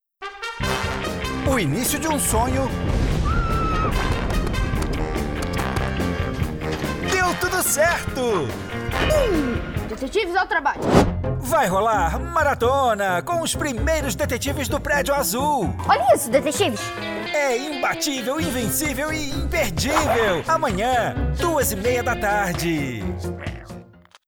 Masculino
Voz Jovem 00:24
• Tenho voz leve e versátil, e interpretação mais despojada.